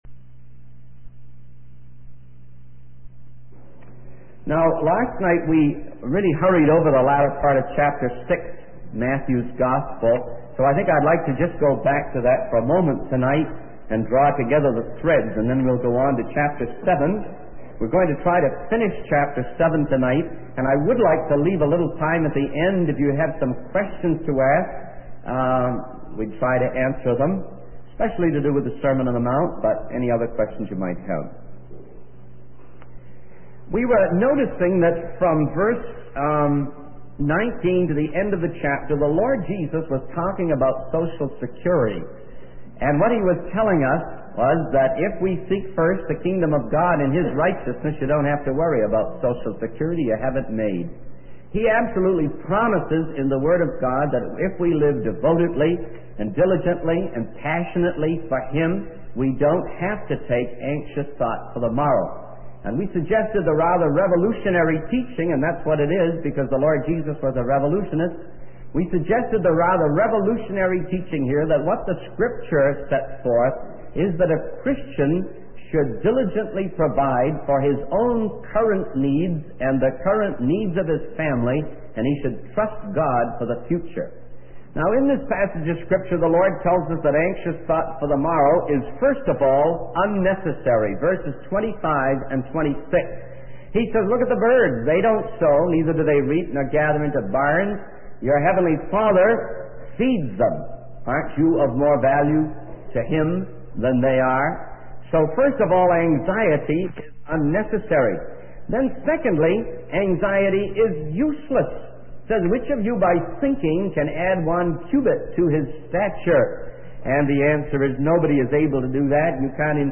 The sermon concludes with a song about Jesus' sacrifice on the cross and a story about a man who was transformed by God's love and sacrifice.